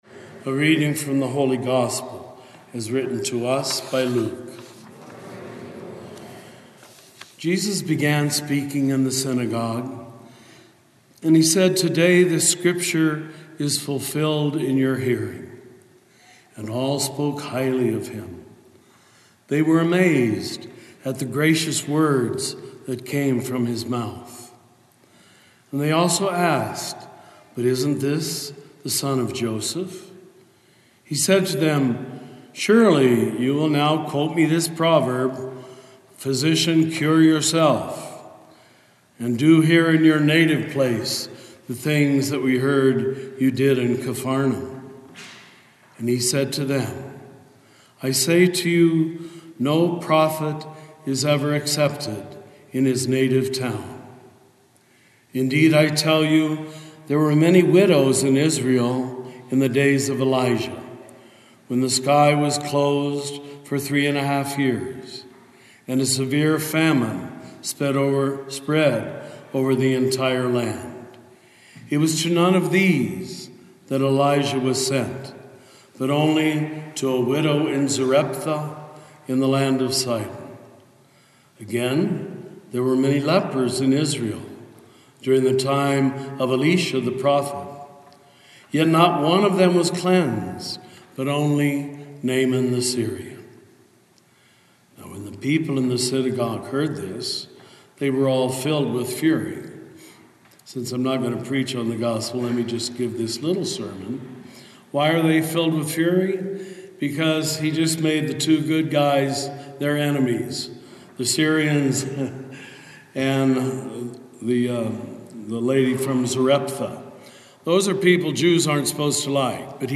Homilies with Richard Rohr